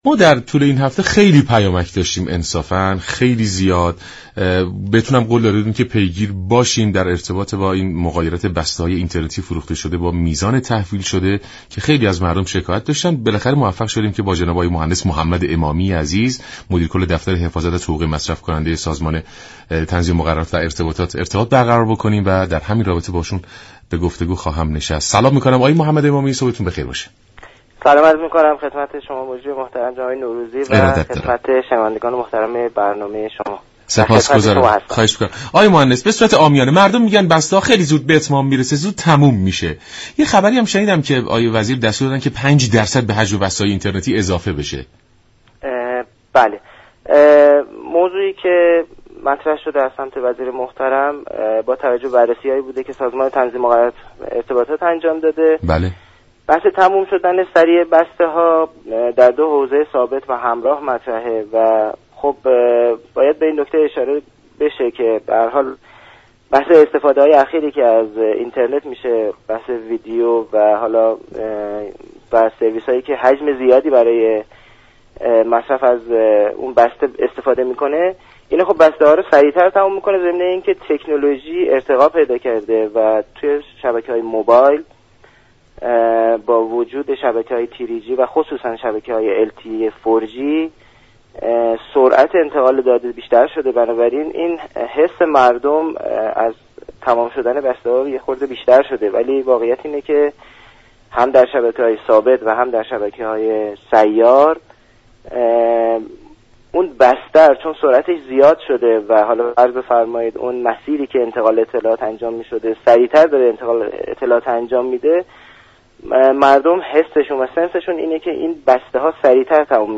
برنامه سلام صبح بخیر شنبه تا پنج شنبه هر هفته از رادیو ایران پخش می شود این گفت و گو را در ادامه می شنوید.